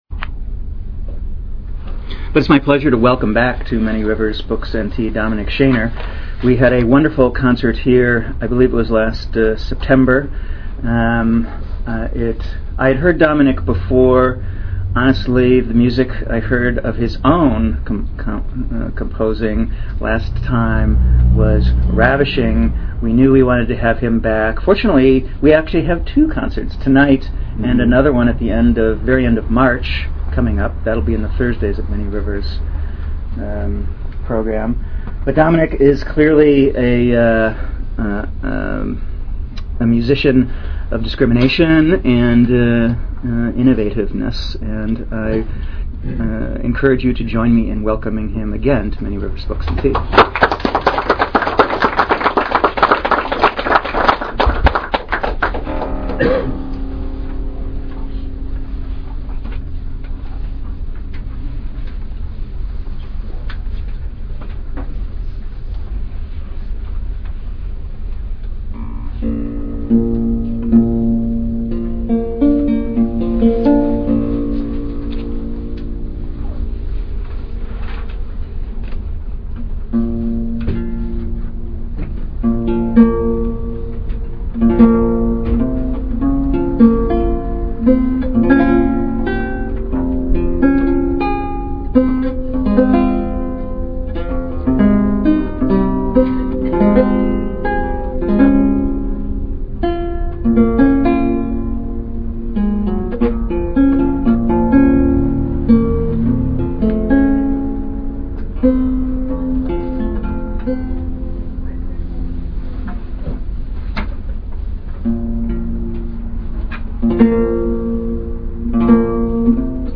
Archive of an event at Sonoma County's largest spiritual bookstore and premium loose leaf tea shop.
archlute and guitar